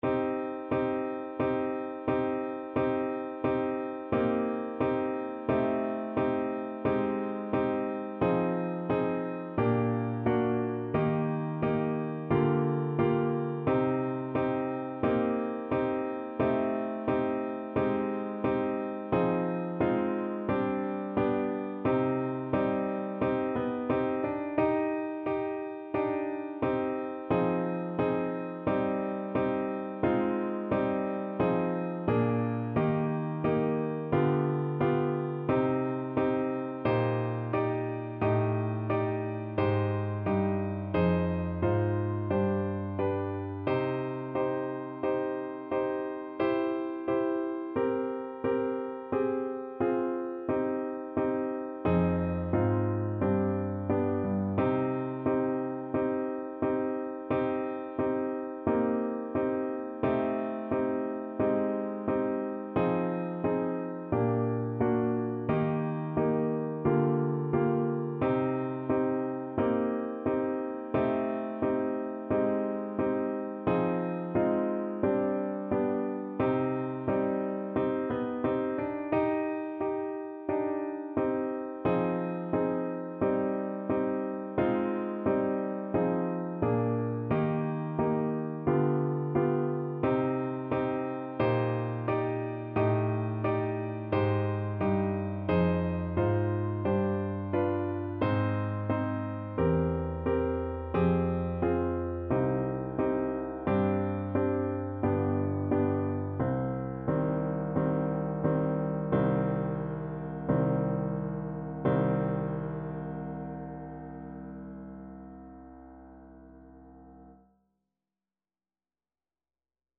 Play (or use space bar on your keyboard) Pause Music Playalong - Piano Accompaniment Playalong Band Accompaniment not yet available reset tempo print settings full screen
C minor (Sounding Pitch) D minor (Clarinet in Bb) (View more C minor Music for Clarinet )
4/4 (View more 4/4 Music)
Andante =c.88